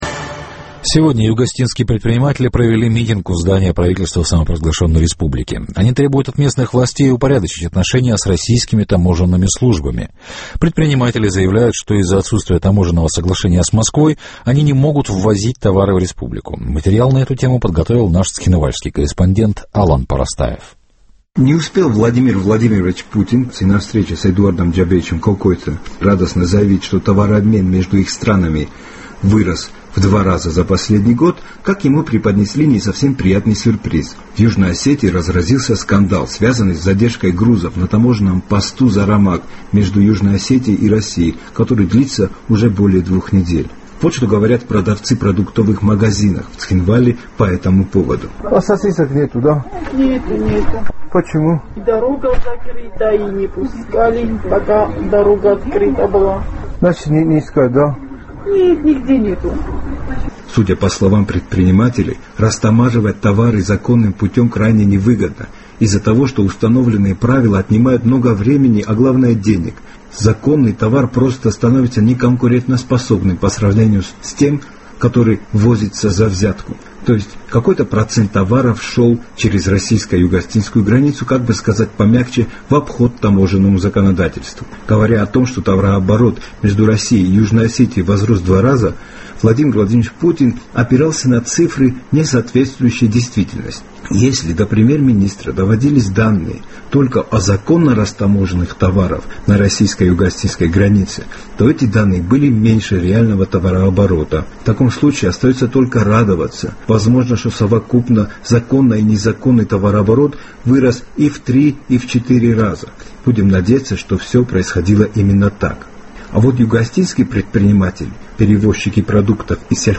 Вот что сказали мне продавцы магазинов в Цхинвале: